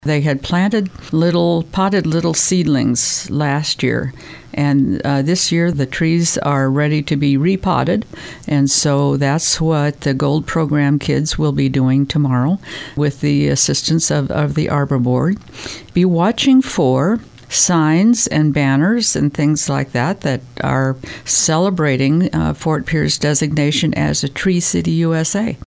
Fort Pierre Mayor Gloria Hanson says their Arbor Board and GOLD Program students will plant trees tomorrow (April 28, 2023), which is Arbor Day.